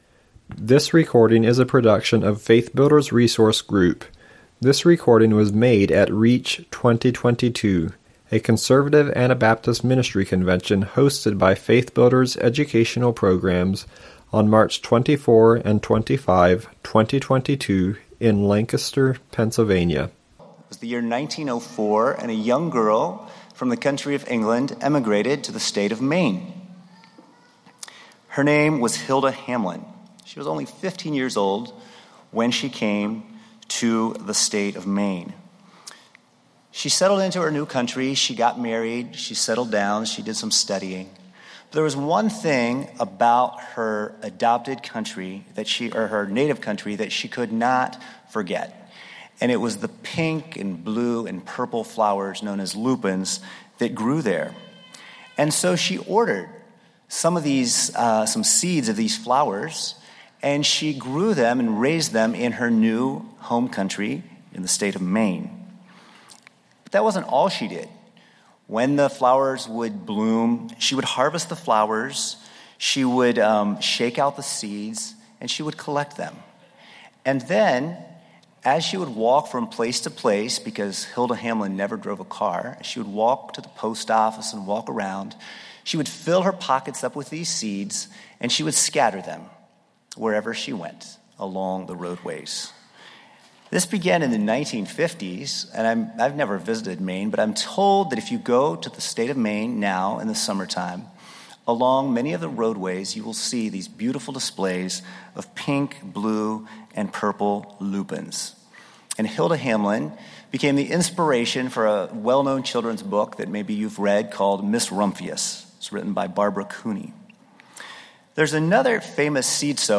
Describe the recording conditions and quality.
Bearing Precious Seed was presented at REACH 2022.